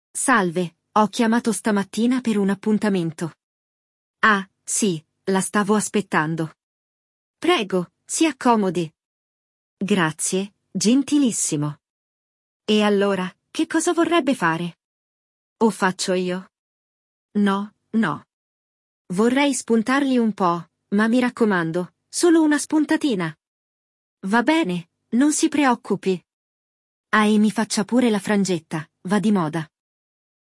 Il dialogo